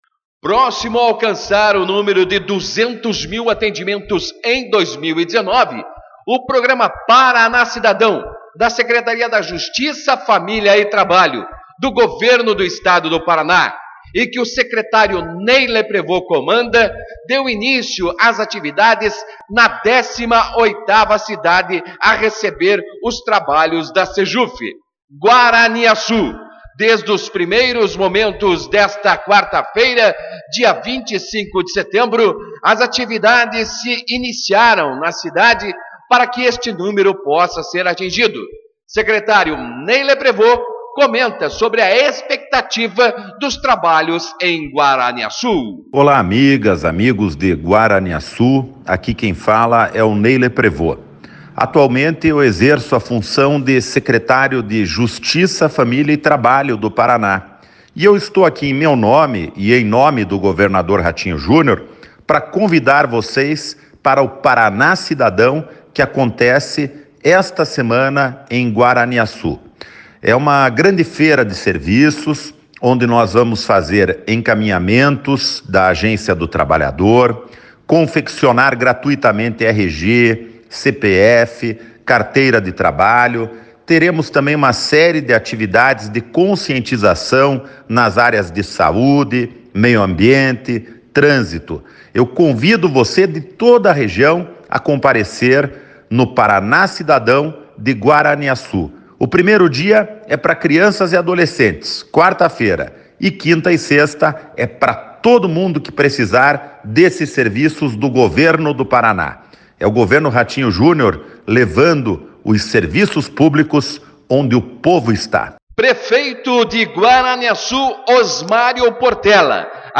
Secretário Ney Leprevost e Prefeito de Guaraniaçu, Osmário Portela falam sobre o Paraná Cidadão em Guaraniaçu